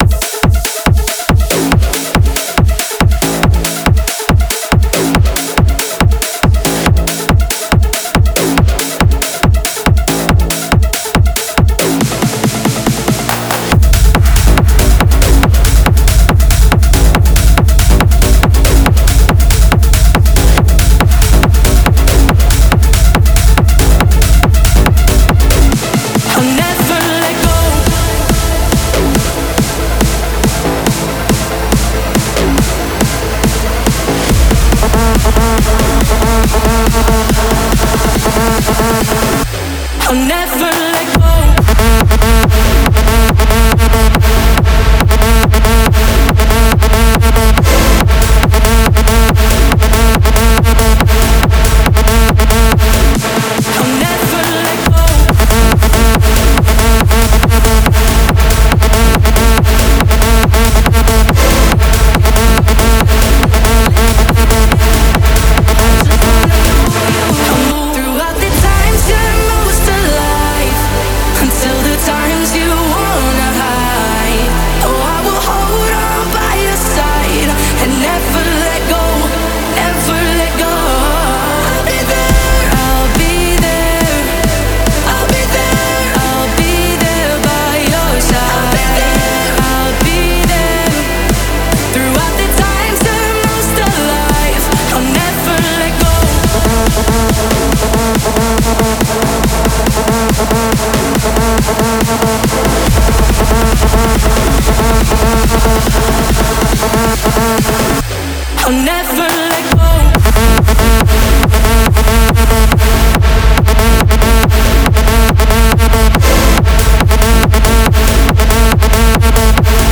试听文件为低音质，下载后为无水印高音质文件 M币 10 超级会员 M币 6 购买下载 您当前未登录！